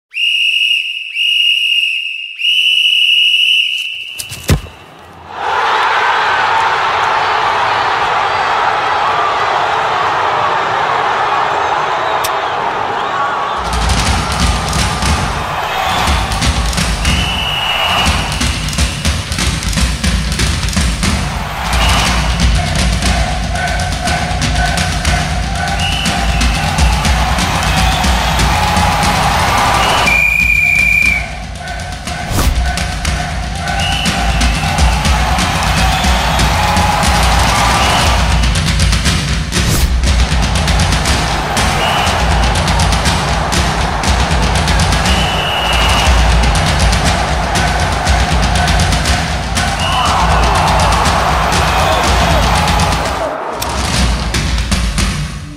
Tổng hợp âm thanh Bóng Đá, tiếng reo hò sân vận động, bàn thắng…
Thể loại: Tiếng động
Description: Tổng hợp các âm thanh bóng đá, tiếng còi, tiếng reo hò khán giả trên sân vận động, bàn thắng....
tong-hop-am-thanh-bong-da-tieng-reo-ho-san-van-dong-ban-thang-www_tiengdong_com.mp3